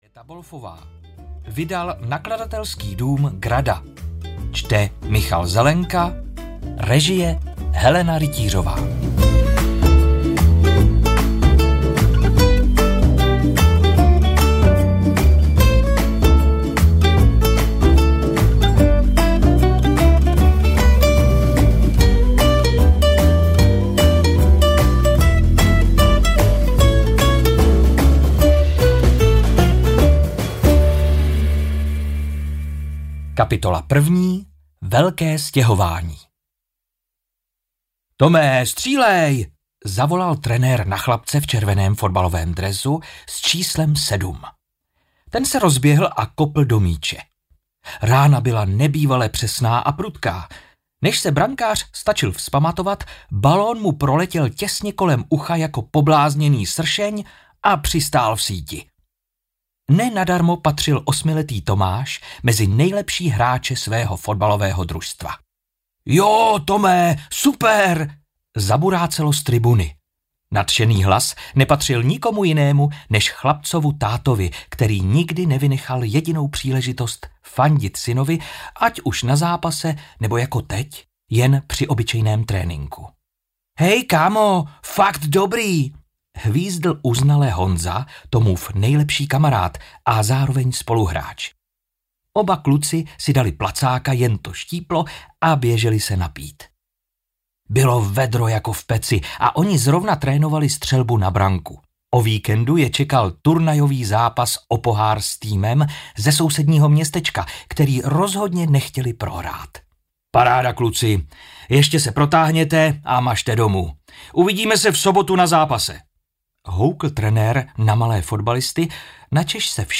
Kluk v kopačkách audiokniha
Ukázka z knihy
kluk-v-kopackach-audiokniha